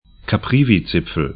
Pronunciation
Caprivizipfel ka'privi-